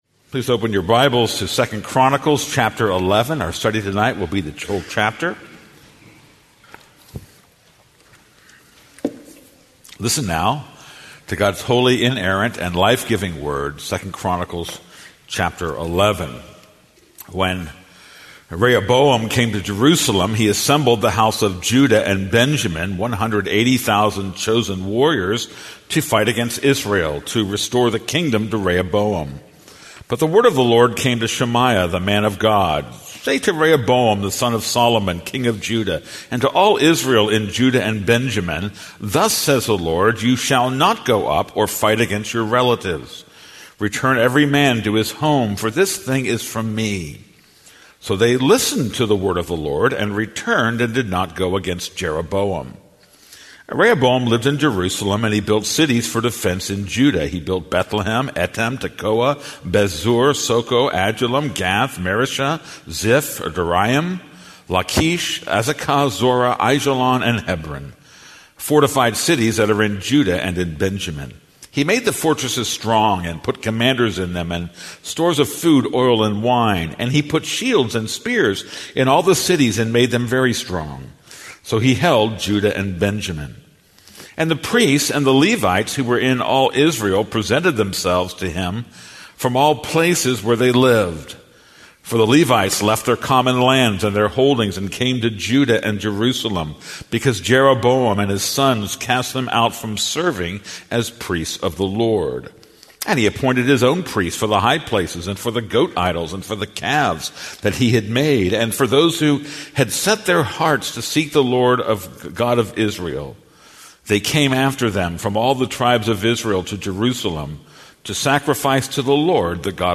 This is a sermon on 2 Chronicles 11:1-23.